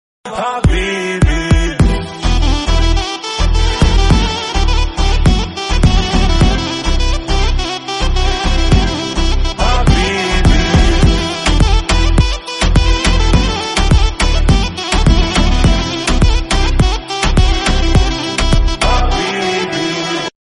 • Качество: 64, Stereo
мужской голос
восточные
арабские
яркие
Яркий рингтон с восточными мотивами